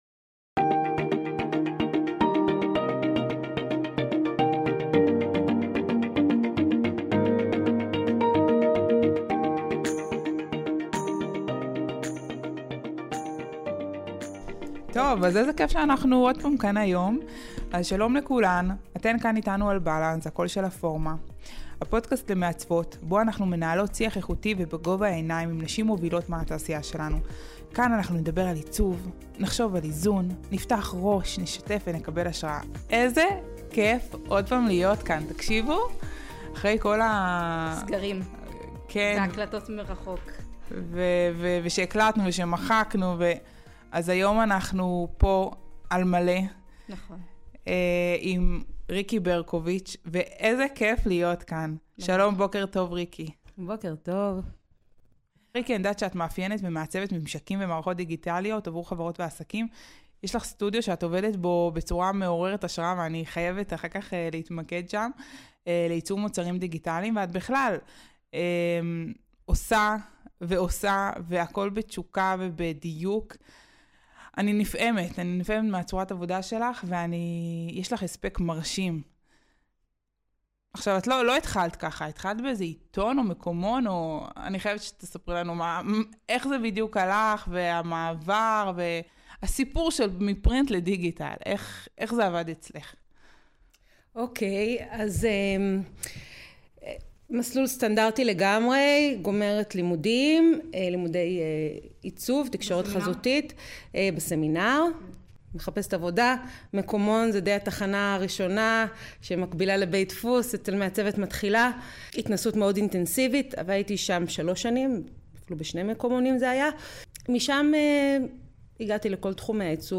השיחה הפעם היתה דואט מענין בין שותפות קרובות.